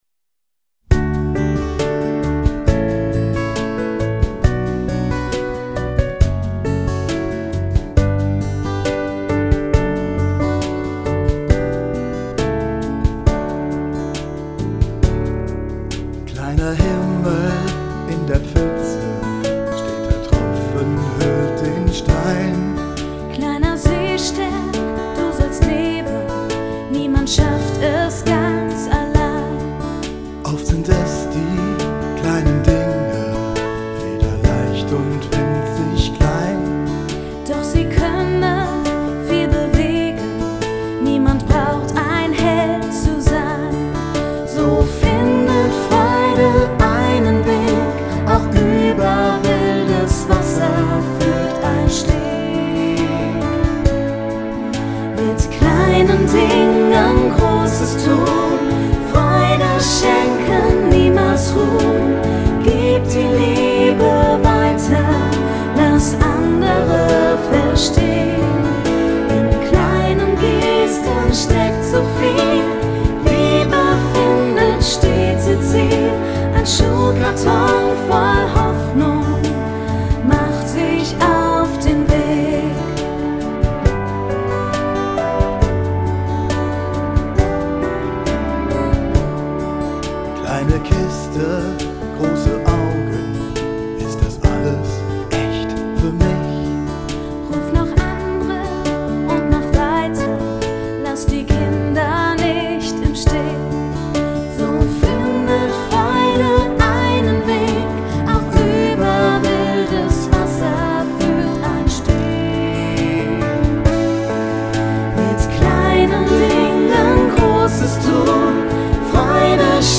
Lied für den Kindergottesdienst (Audio)